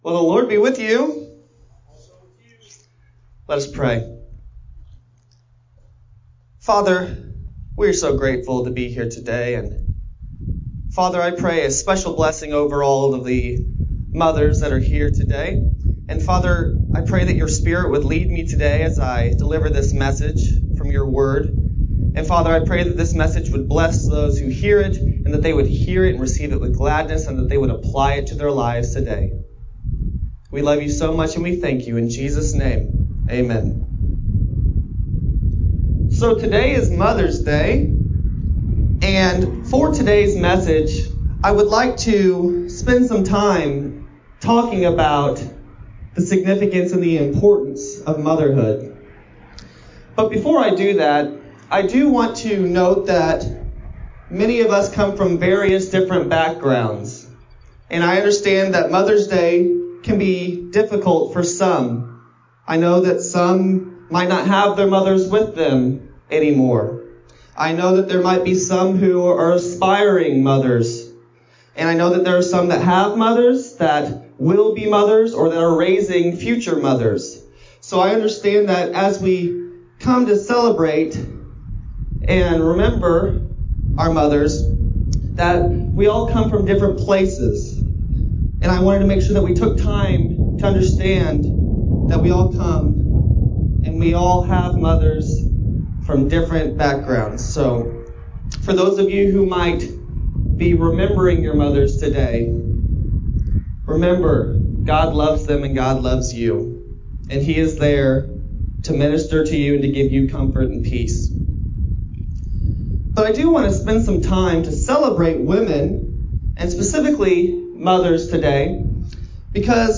5-10-20-Sermon-BU-CD.mp3